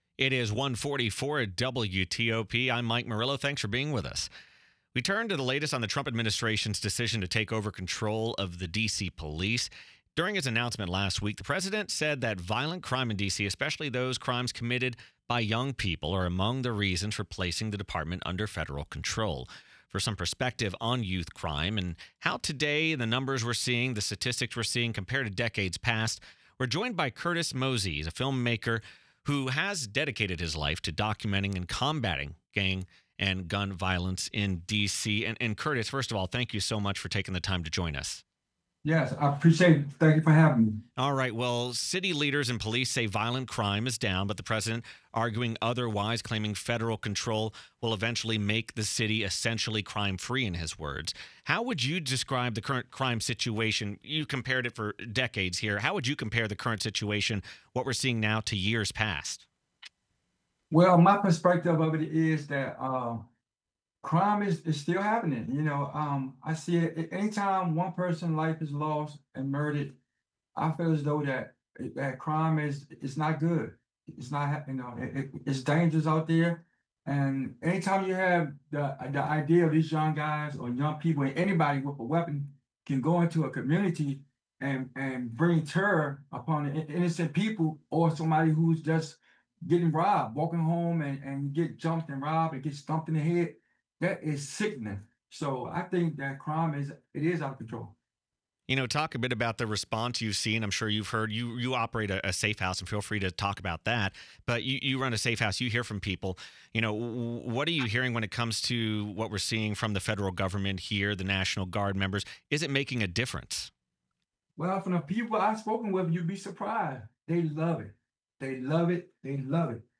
Live Radio
Q&A: Filmmaker discusses youth crime and Trump’s federal policing takeover in DC